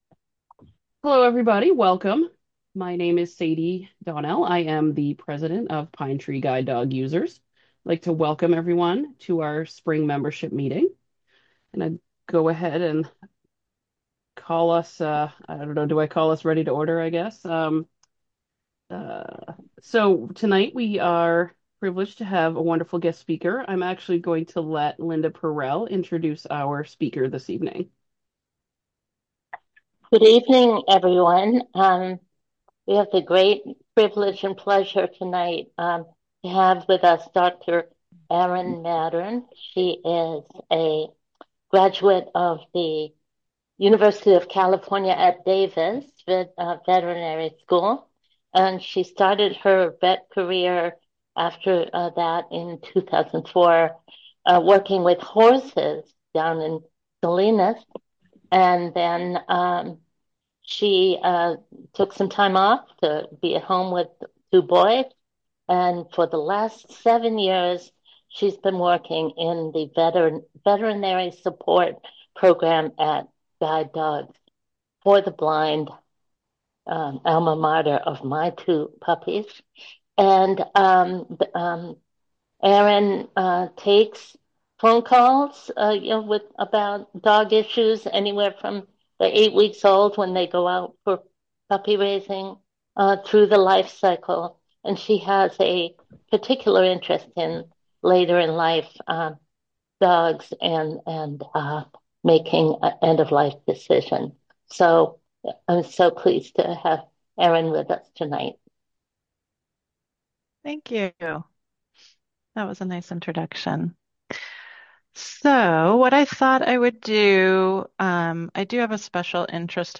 PTGDU-5-7-25-Presentation-Recording.mp3